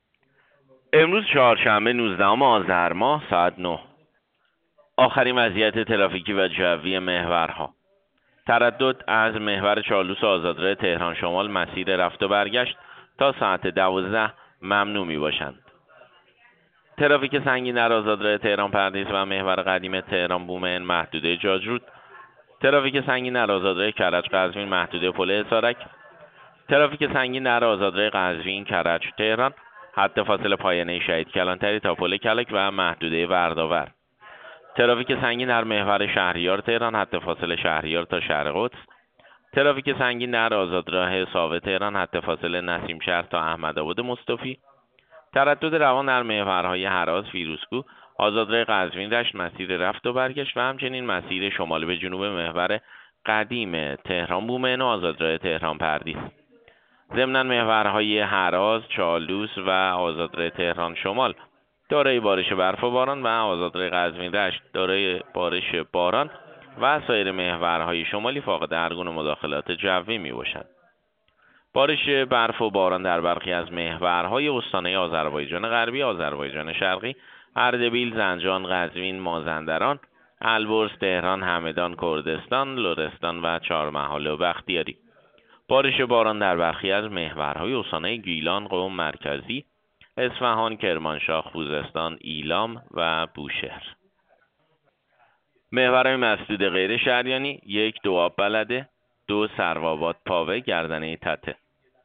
گزارش رادیو اینترنتی از آخرین وضعیت ترافیکی جاده‌ها ساعت ۹ هجدهم آذر؛